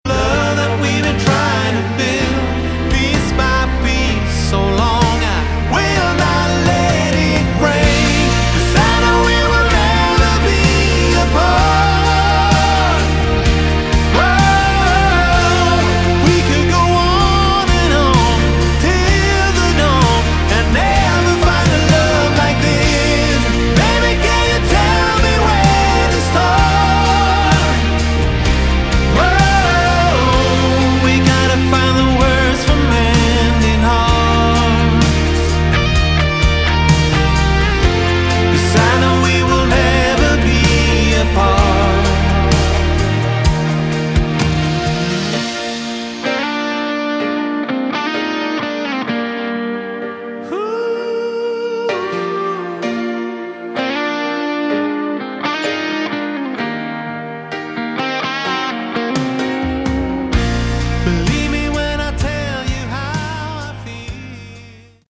Category: AOR / Melodic Rock
lead vocals, guitars
keyboards
bass
drums